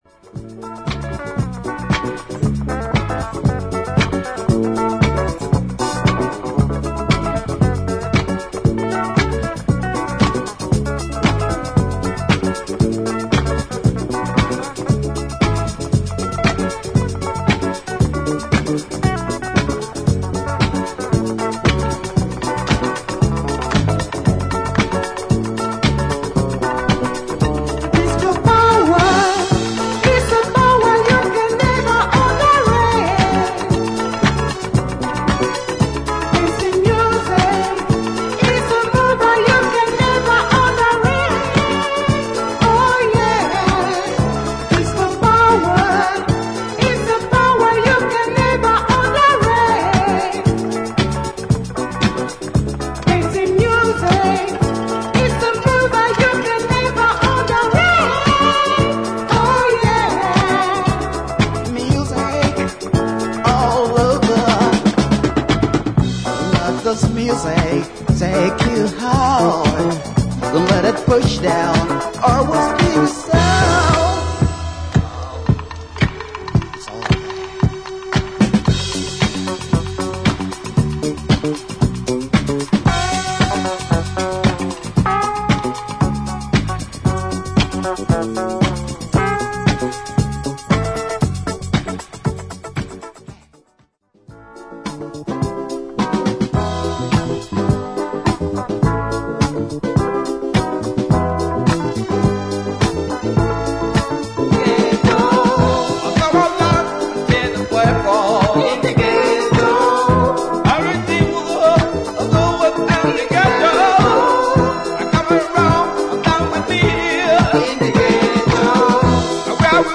哀愁感のある極上のアフロ・ディスコ・サウンドを展開する
ファンクネス感ある同系のナイジェリアン・ブギー